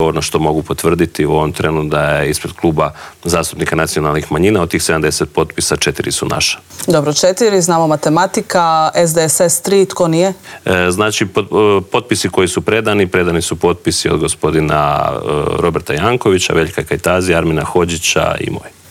ZAGREB - Nakon što je predsjednik HDZ-a Andrej Plenković predao 78 potpisa i od predsjednika Zorana Milanovića dobio mandat da treći put zaredom sastavi Vladu, saborski zastupnik češke i slovačke nacionalne manjine Vladimir Bilek otkrio je u Intervjuu tjedna Media servisa tko je od manjinaca dao svoj potpis.